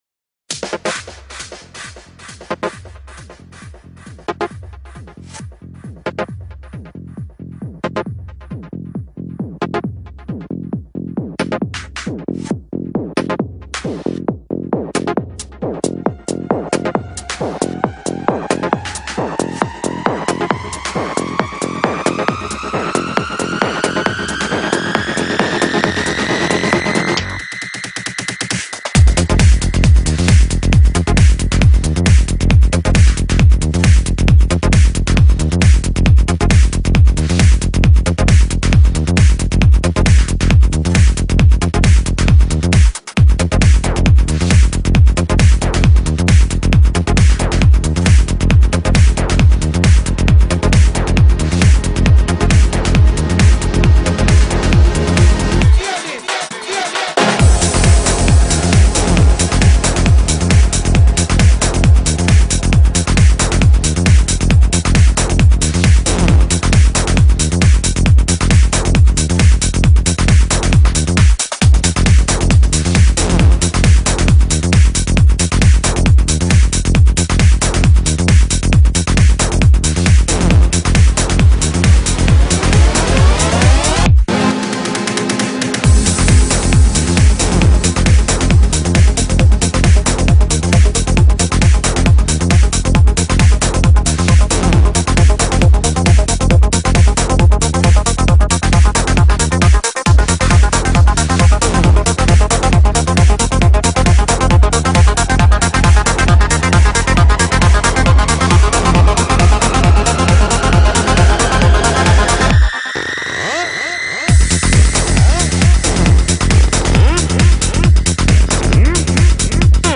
trance progresivo